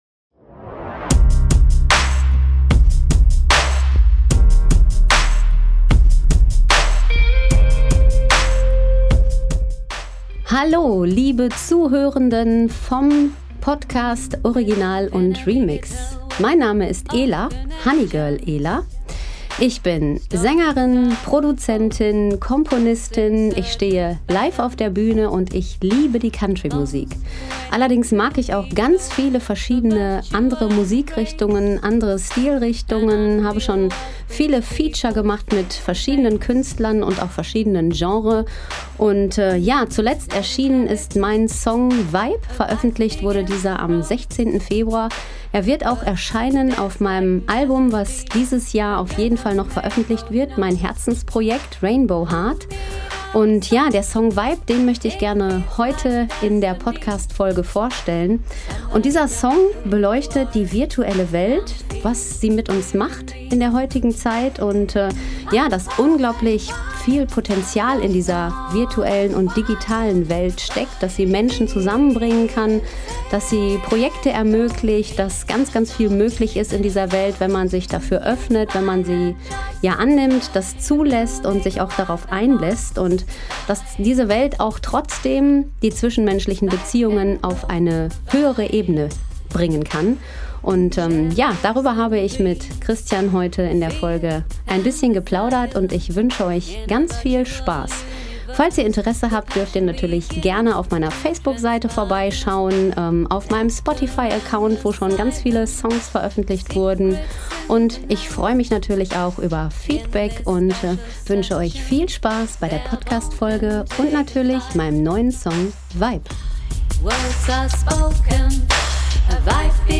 Das große Interview